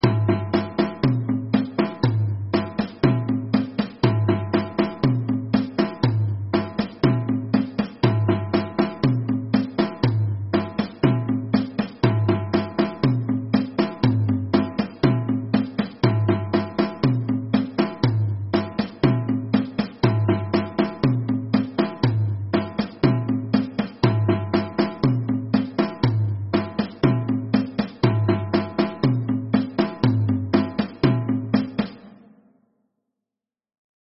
piraja-repinique.mp3